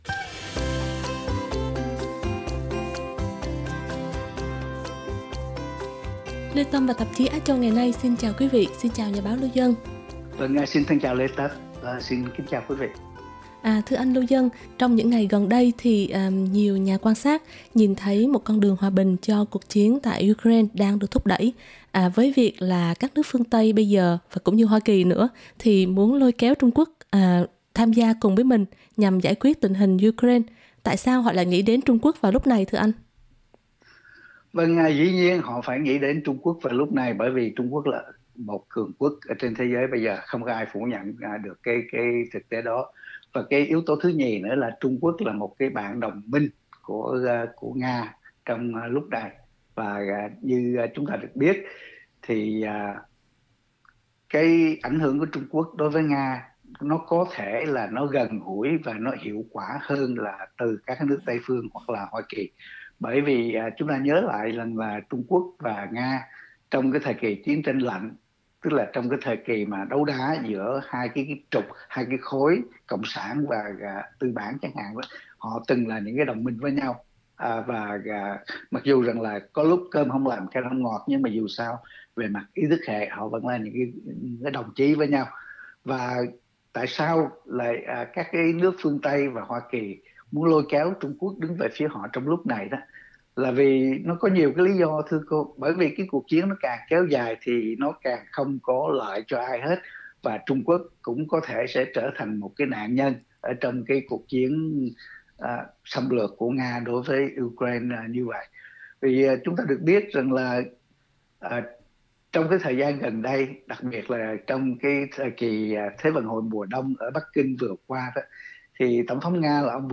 bài bình luận